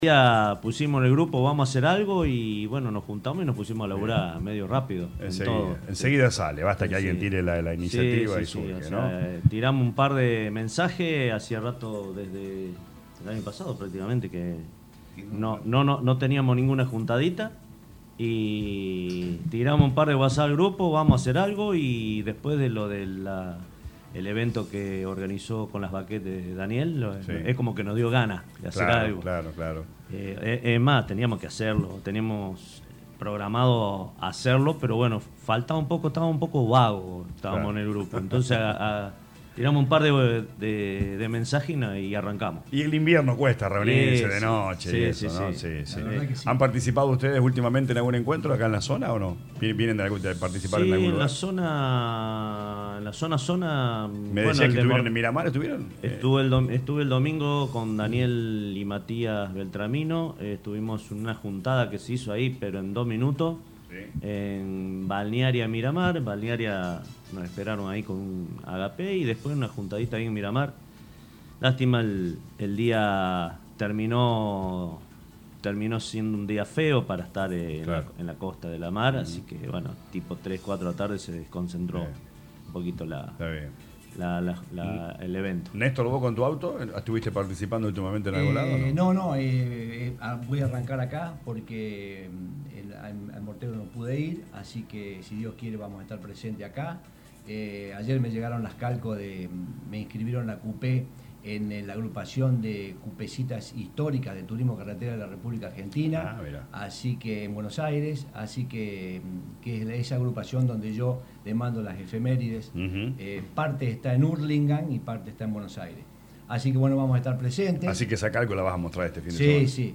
en diálogo con LA RADIO 102.9 FM